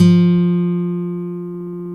Index of /90_sSampleCDs/Roland L-CD701/GTR_Nylon String/GTR_Classical